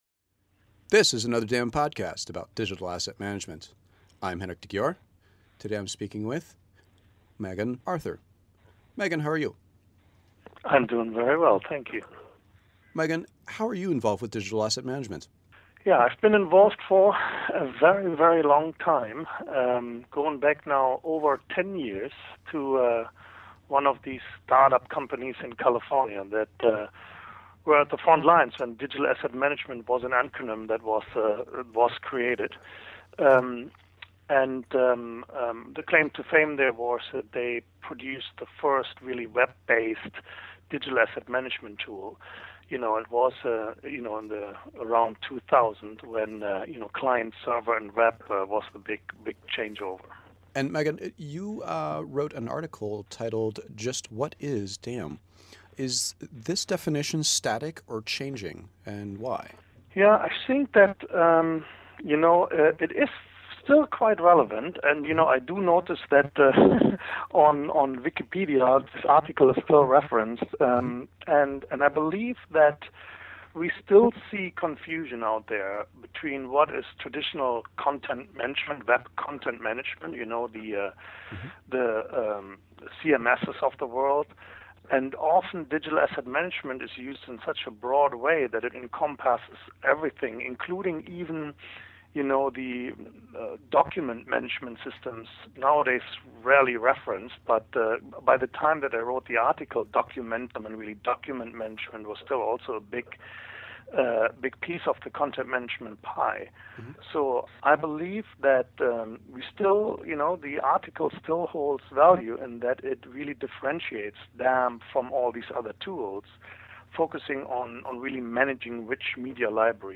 Here are the questions asked: